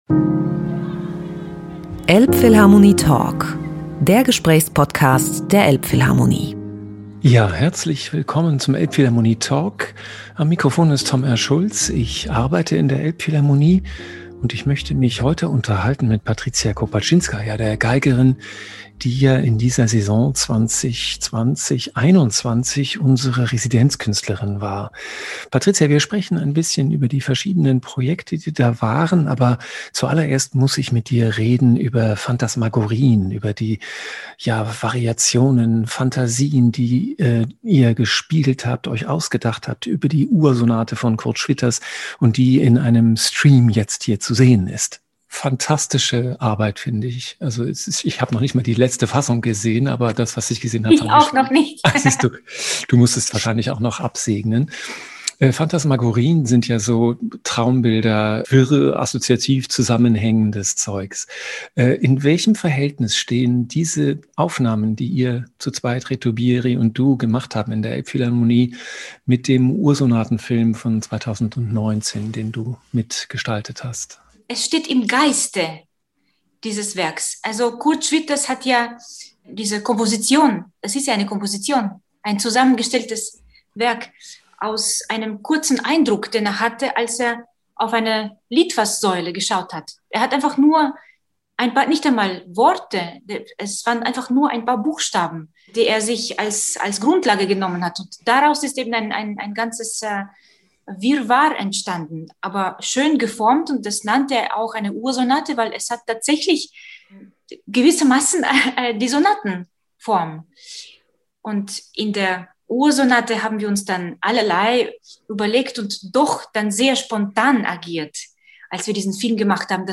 elbphilharmonie-talk-mit-patricia-kopatchinskaja-mmp.mp3